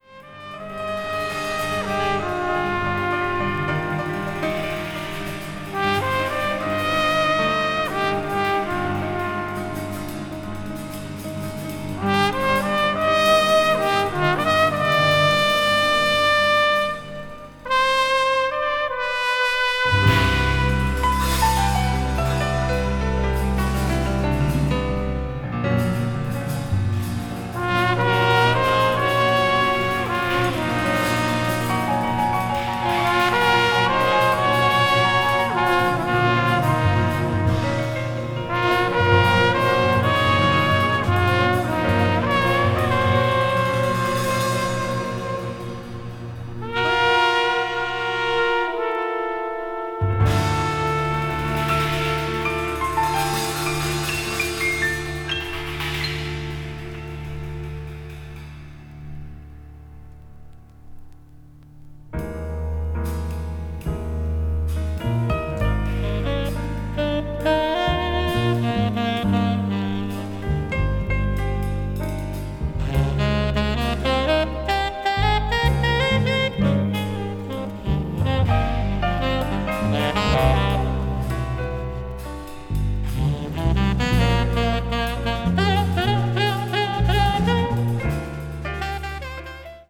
media : EX/EX(some slightly noises.)